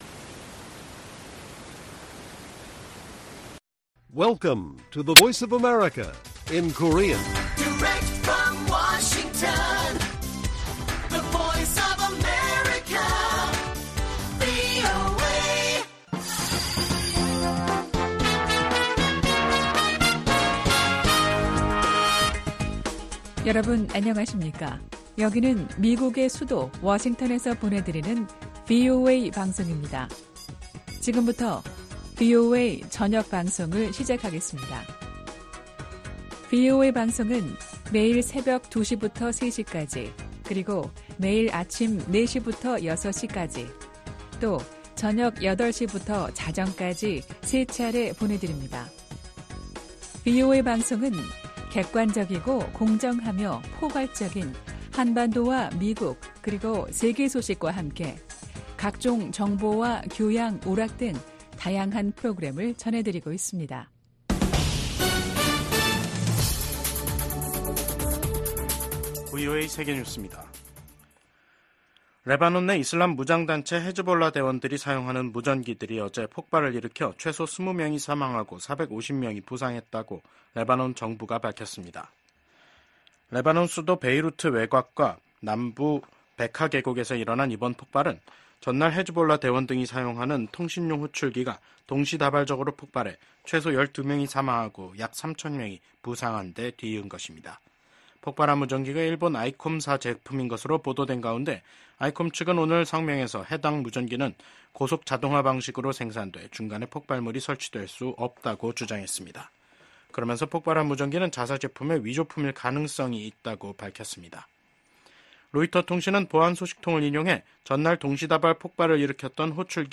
VOA 한국어 간판 뉴스 프로그램 '뉴스 투데이', 2024년 9월 19일 1부 방송입니다. 북한은 고중량 고위력의 재래식 탄두를 장착한 신형 단거리 탄도미사일 시험발사에 성공했다고 밝혔습니다. 미국 국무부 부장관이 북한과 러시아의 협력이 북한으로 하여금 더 도발적인 행위를 하도록 부추길 우려가 있다고 말했습니다. 유럽연합과 영국 프랑스, 독일 등 유럽 주요국이 엿새 만에 재개된 북한의 미사일 발사를 강력하게 규탄했습니다.